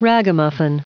Prononciation du mot ragamuffin en anglais (fichier audio)
Prononciation du mot : ragamuffin
ragamuffin.wav